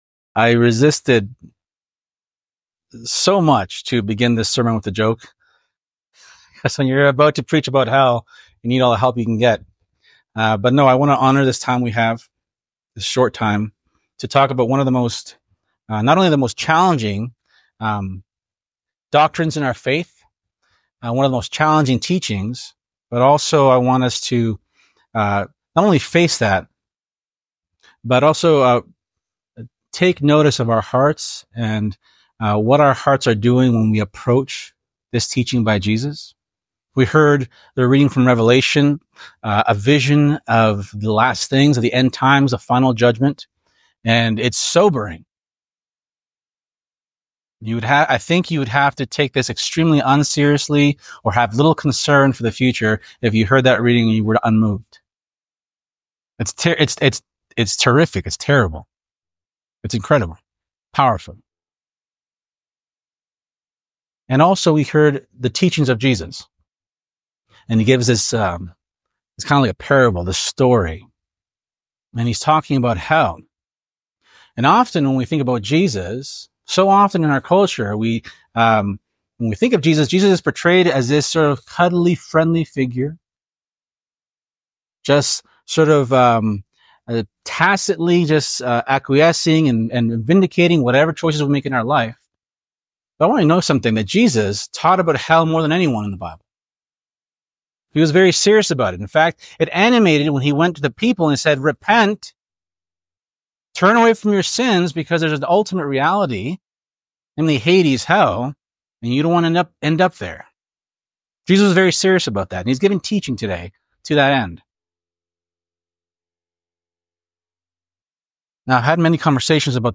Sermons | St. George in the Pines Anglican Church